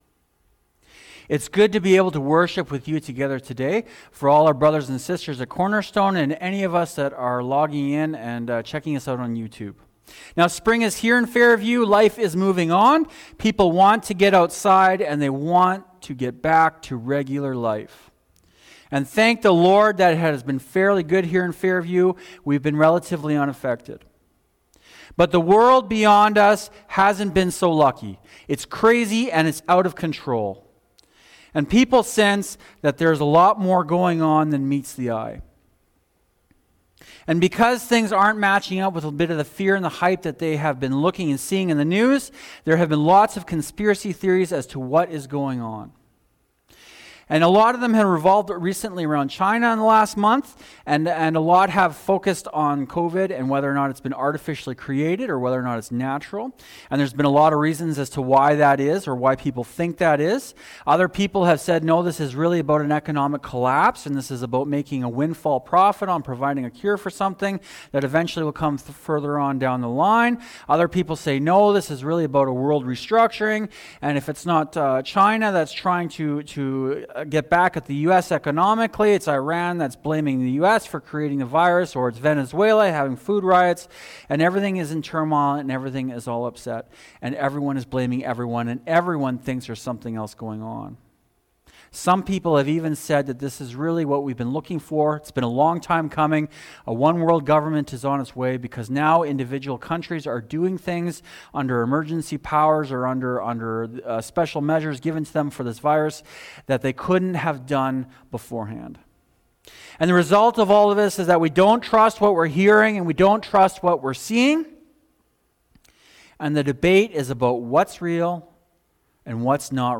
sermon-April-25.mp3